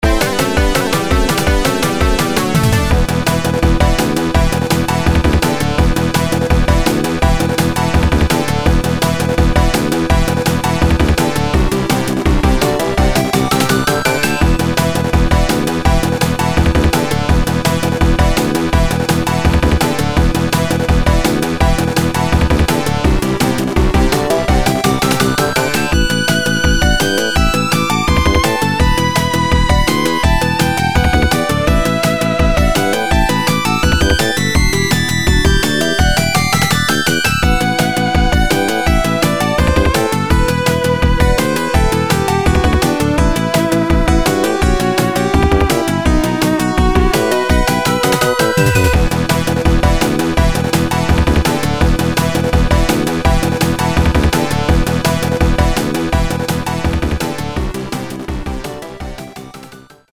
ドラムパートをのぞけばメロディーはほとんどいじってません。